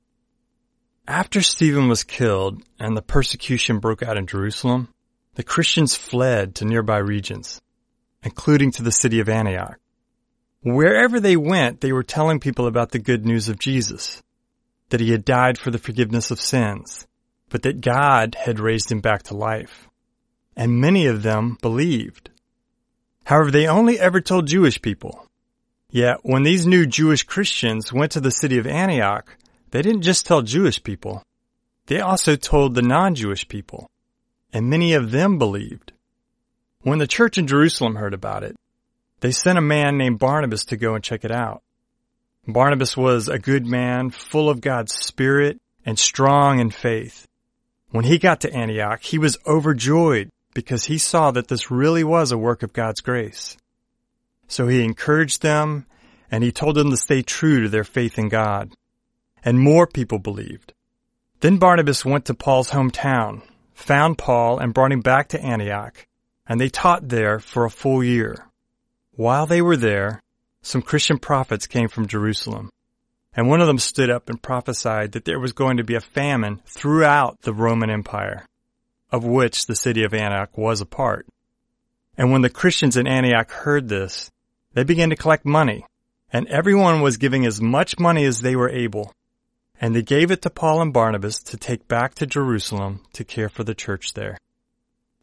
Deepen your intimacy with God by listening to an oral Bible story each day.